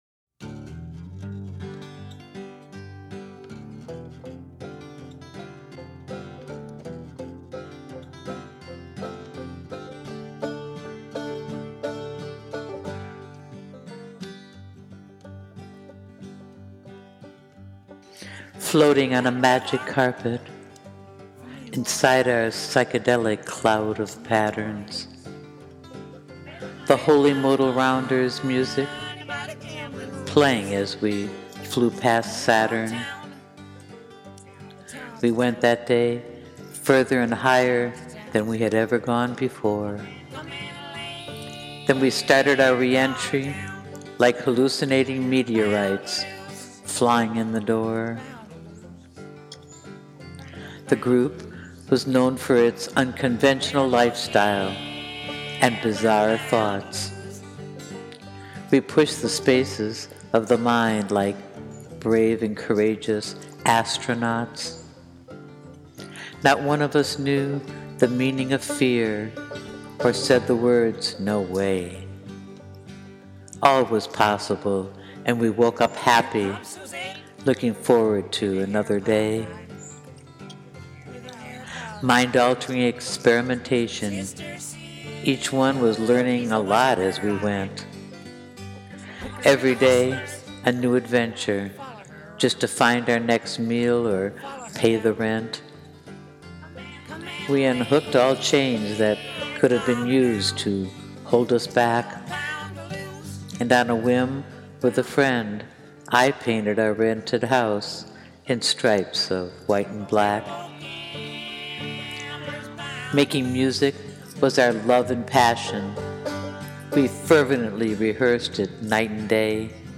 rock-n-roll-60s.mp3